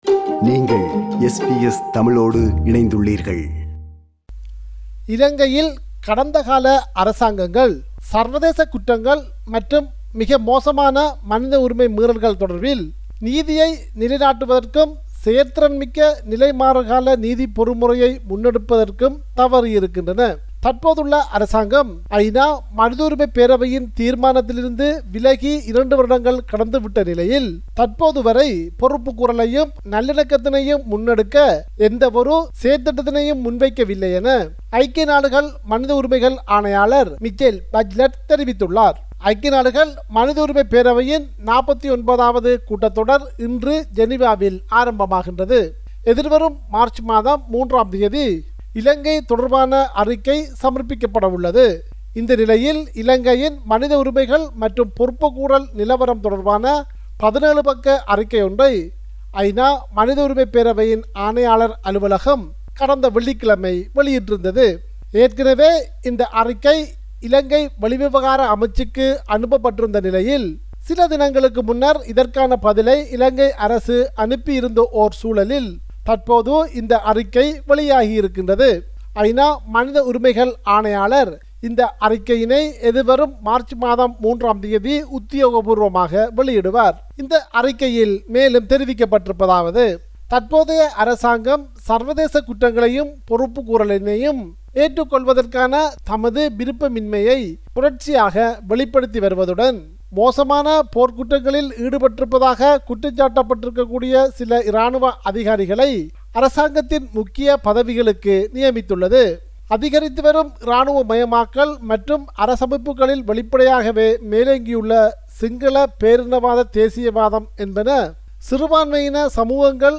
compiled a report focusing on major events/news in North & East / Sri Lanka.